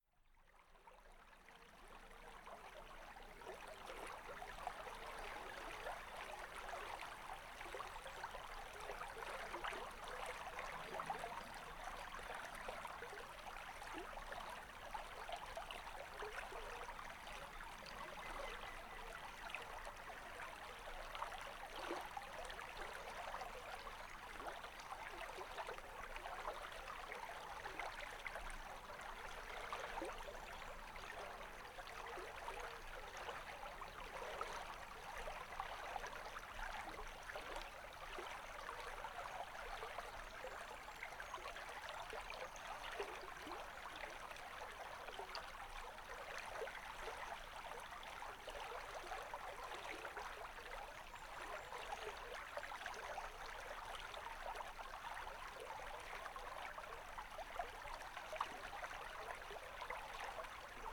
Category 🎵 Relaxation